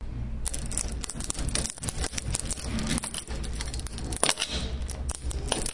金属碰撞
描述：金属碰撞声
Tag: 无比 金属